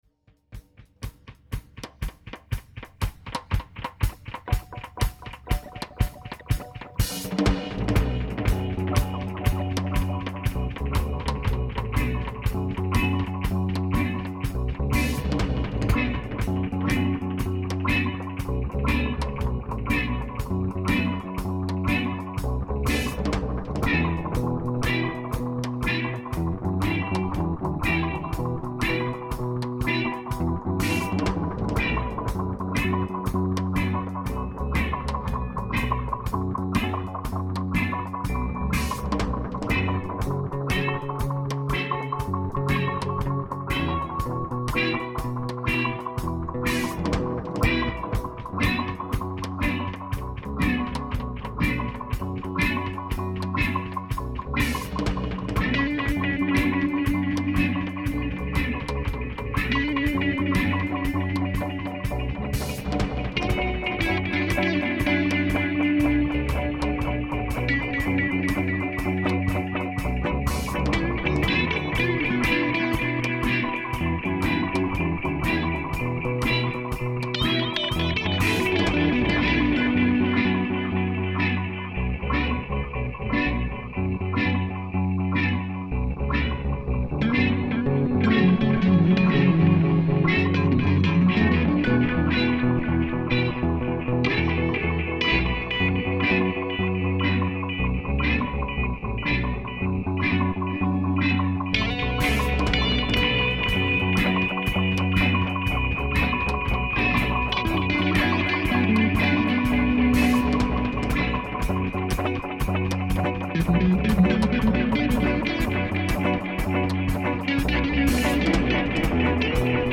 At the top of the post is my homage to Dub music.